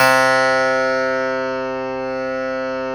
53s-pno05-C1.aif